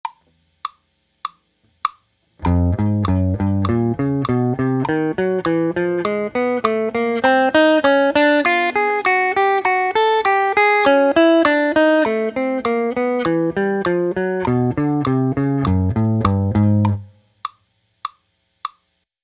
This is the third of eight sets of chromatic exercises.